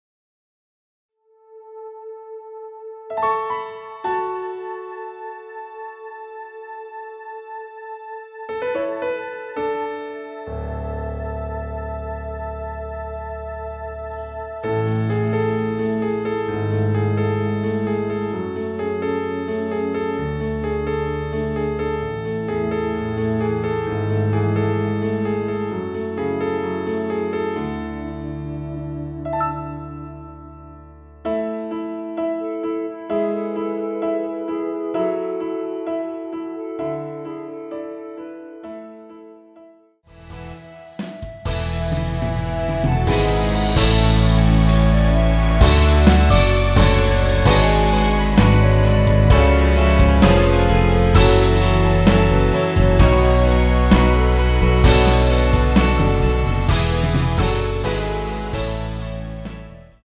대부분의 남성분이 부르실수 있는 키로 제작하였습니다.
원키에서(-6)내린 멜로디 포함된 MR입니다.
노래방에서 노래를 부르실때 노래 부분에 가이드 멜로디가 따라 나와서
앞부분30초, 뒷부분30초씩 편집해서 올려 드리고 있습니다.
중간에 음이 끈어지고 다시 나오는 이유는